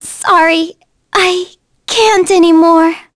Cassandra-Vox_Dead.wav